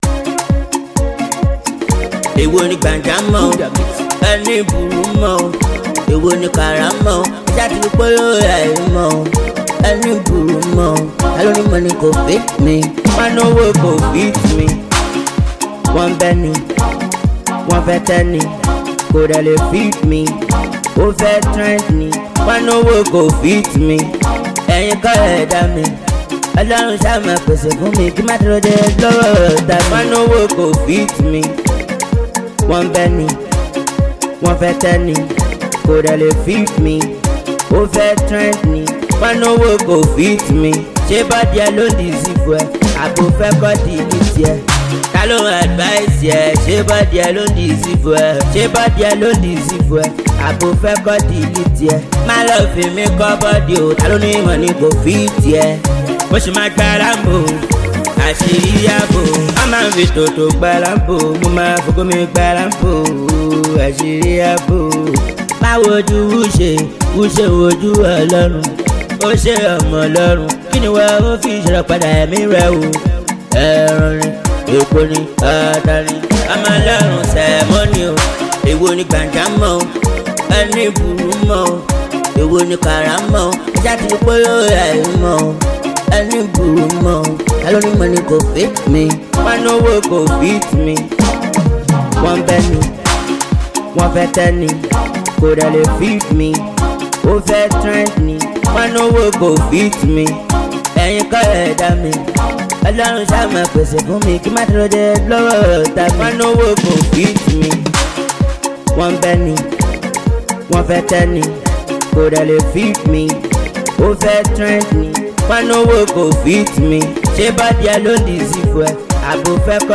Street-hop
street music genre